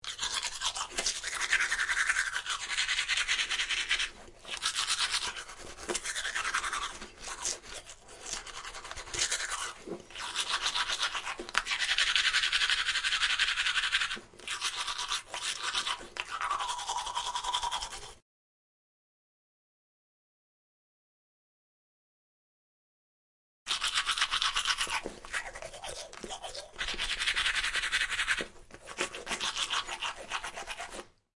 刷牙1
描述：牙齿刷满了它的所有音响。
Tag: 浴室 牙齿刷